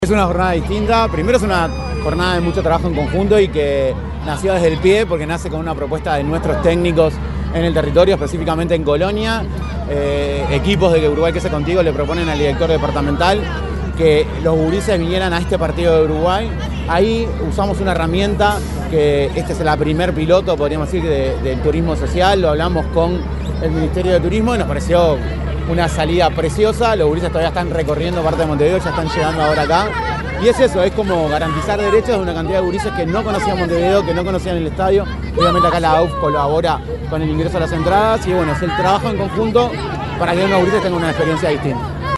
Declaraciones del subsecretario de Desarrollo Social, Federico Graña
Declaraciones del subsecretario de Desarrollo Social, Federico Graña 04/09/2025 Compartir Facebook X Copiar enlace WhatsApp LinkedIn Tras una coordinación entre organismos del Estado, un grupo de niños y adolescentes de Colonia viajaron a Montevideo para presenciar el partido de fútbol entre Uruguay-Perú. En la oportunidad, el subsecretario de Desarrollo Social, Federico Graña, los recibió en el estadio Centenario y realizó declaraciones a la prensa.